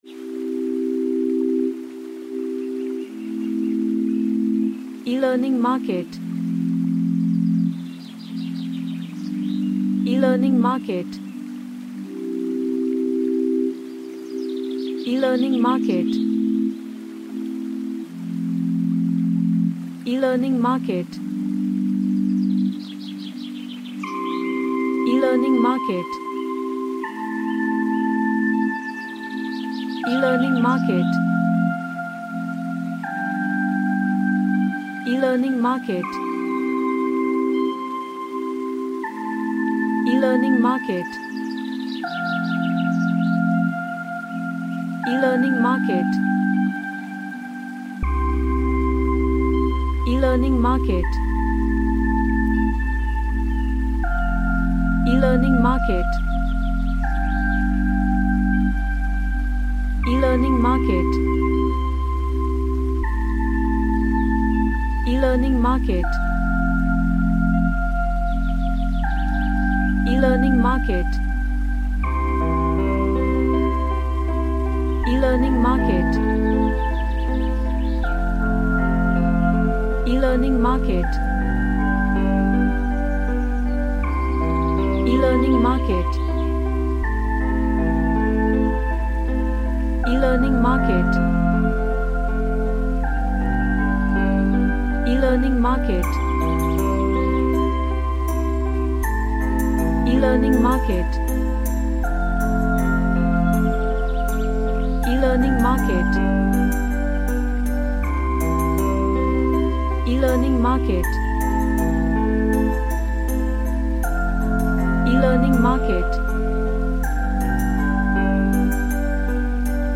A relaxing track with electronic ambience
Relaxation / Meditation